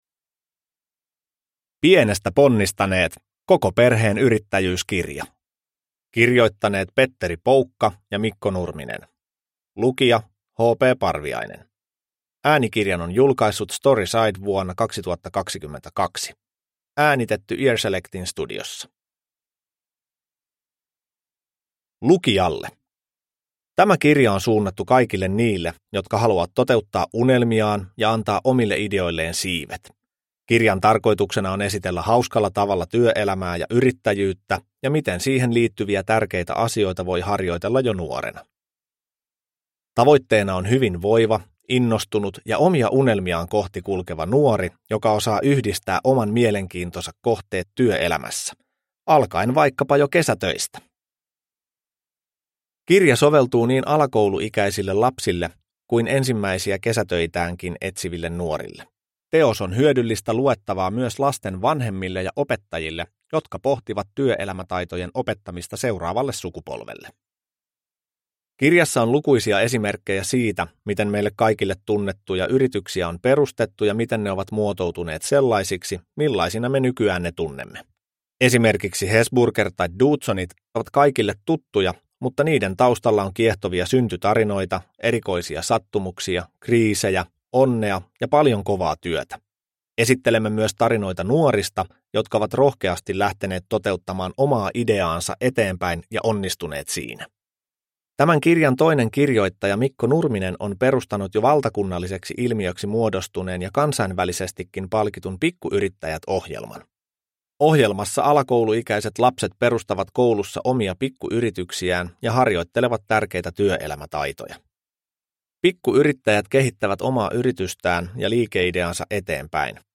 Pienestä ponnistaneet – Ljudbok – Laddas ner